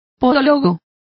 Complete with pronunciation of the translation of chiropodist.